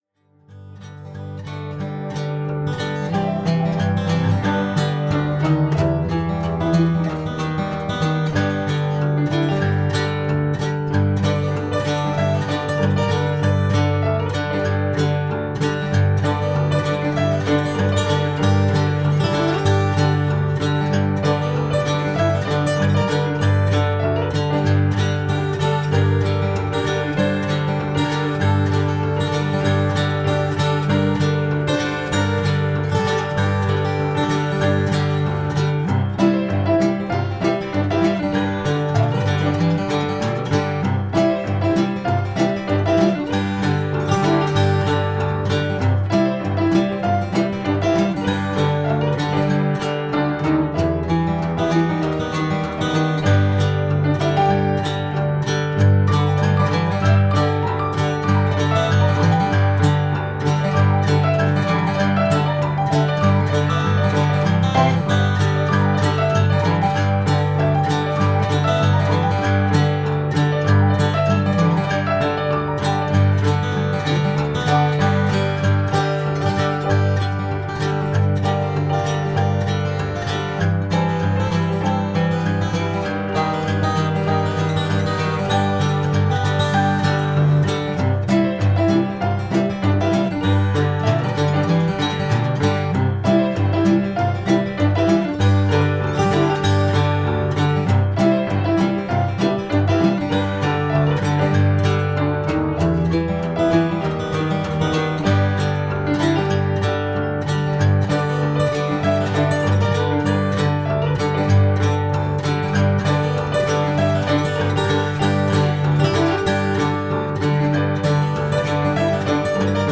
jaunty and infectious
ragtime piano